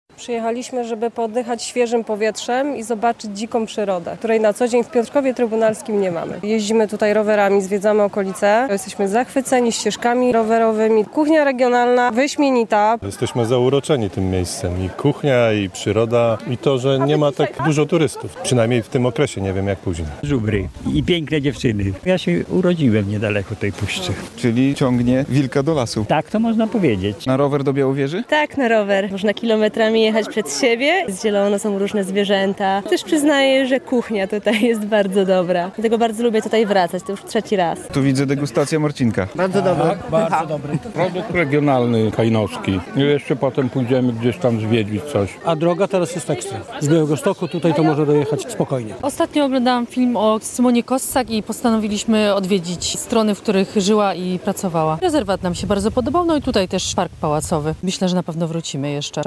Myślę, że na pewno jeszcze wrócimy - mówią turyści, z którymi rozmawialiśmy.